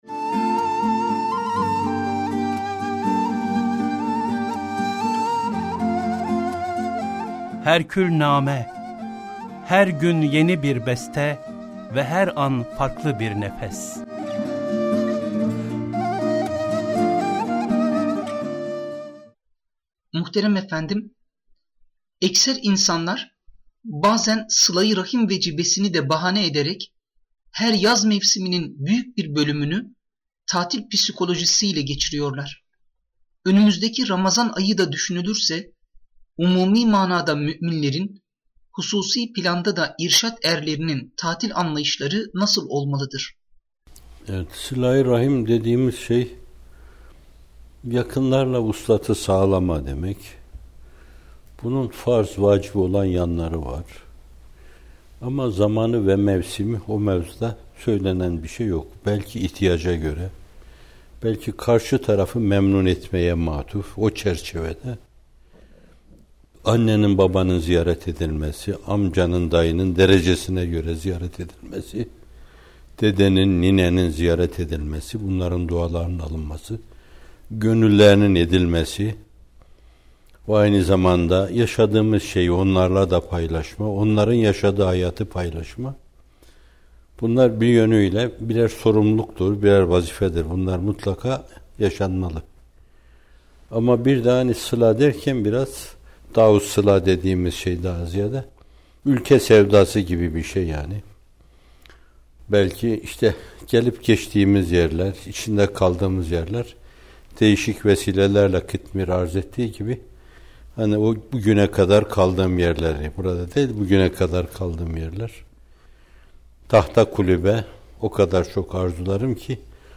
341. Nağme: Sıla-i Rahim, Yaz Tatili ve Ramazan-ı Şerif - Fethullah Gülen Hocaefendi'nin Sohbetleri